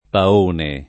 paone [ pa 1 ne ]